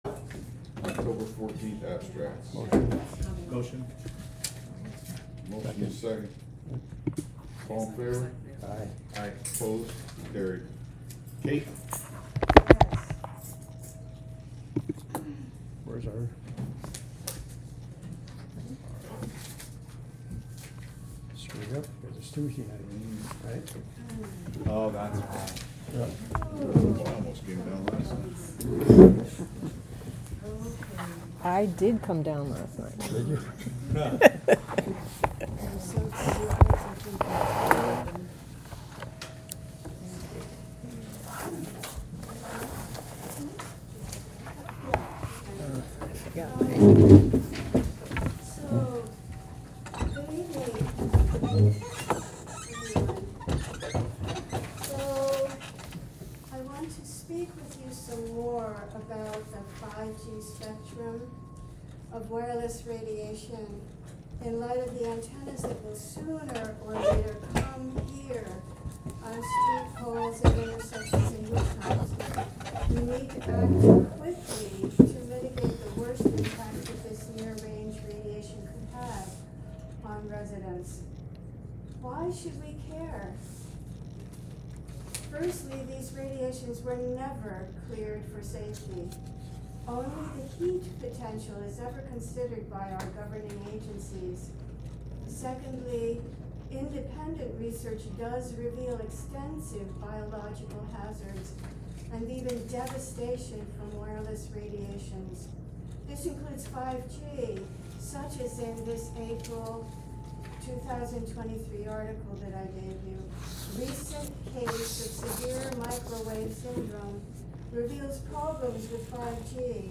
Live from the Village of Philmont: Planning Board Meeting (Audio)